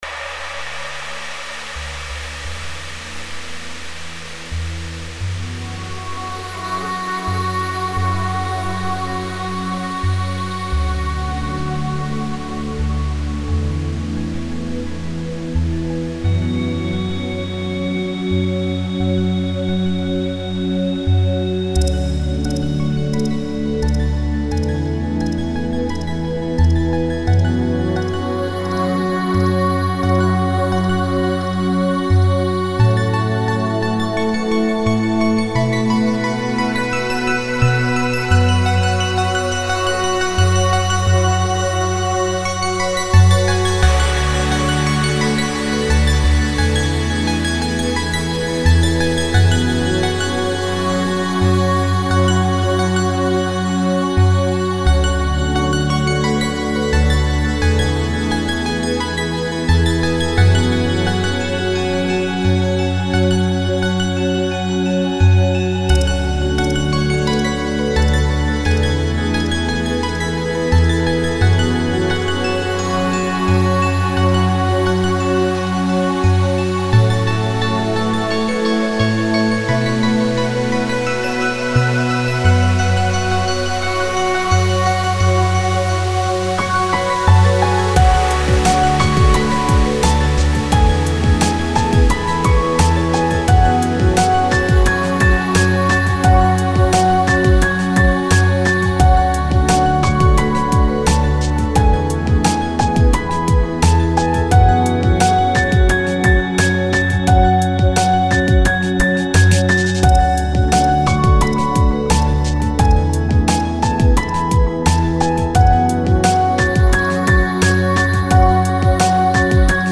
• Категория:Успокаивающая музыка